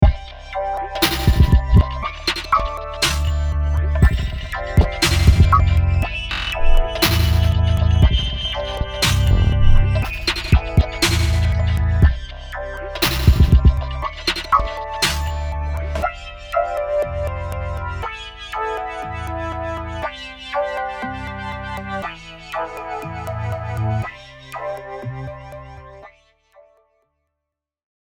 And just for fun, here’s a version of it accompanied by some effected drums:
asequence_drums.mp3